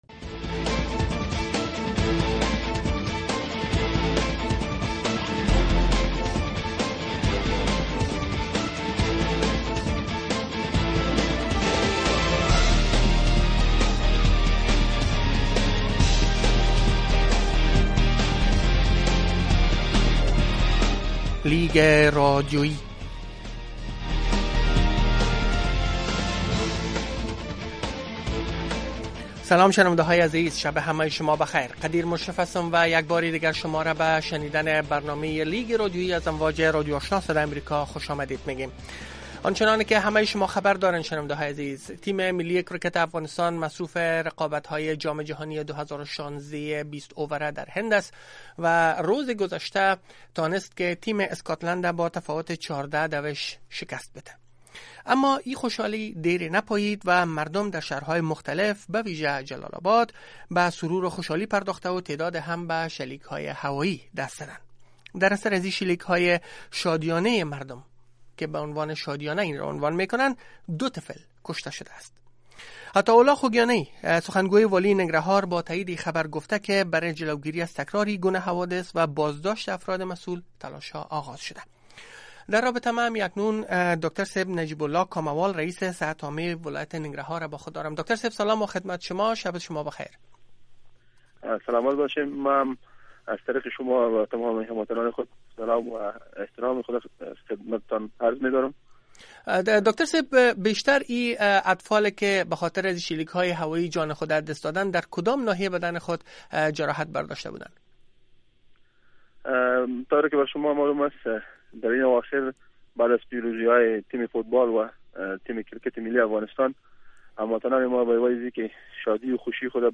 interview about firing guns